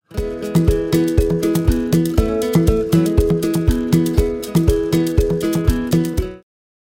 Этот инструмент сопровождает гитару и похож на ксилофон. Но ксилофон более железный что ли...